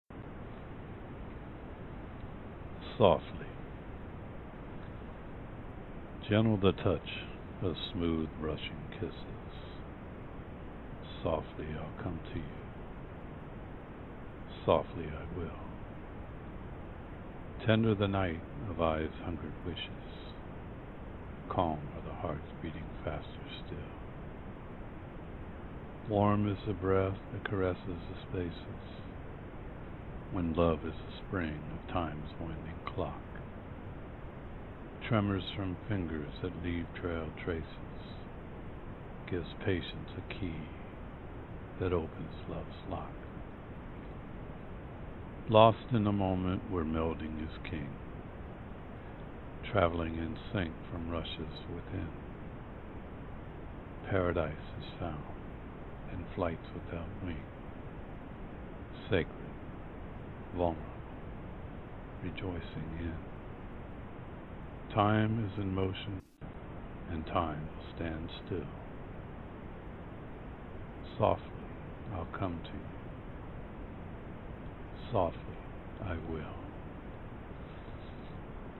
Absolutely thrilling....Not only is the content something that is SO enchanting, but your voice is also wonderful, deep, and strong.
Your pace and meter as you read is just right, with the contemplative pauses needed to take the words all in...nice job!